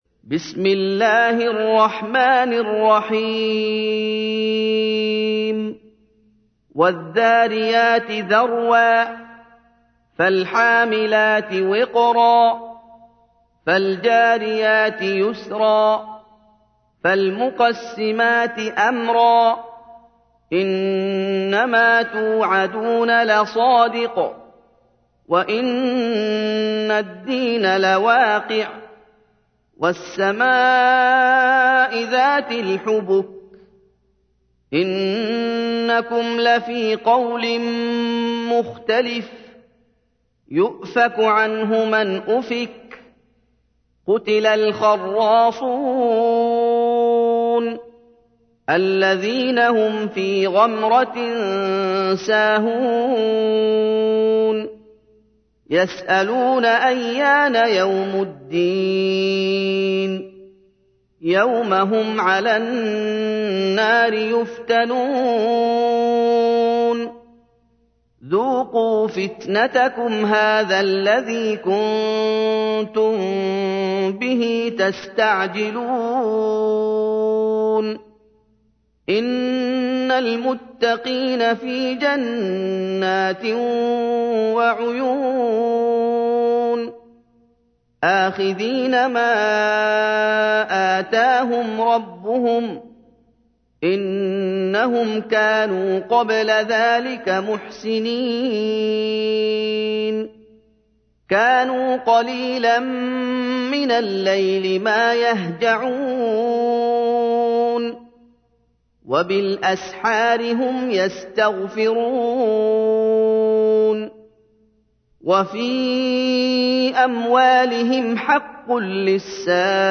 تحميل : 51. سورة الذاريات / القارئ محمد أيوب / القرآن الكريم / موقع يا حسين